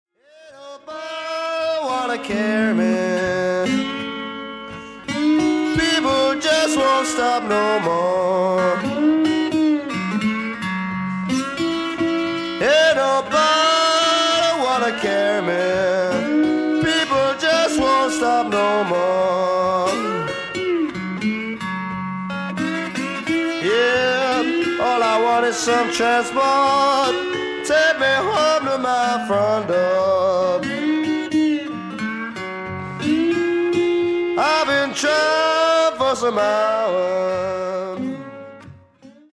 BLUES ROCK / COUNTRY BLUES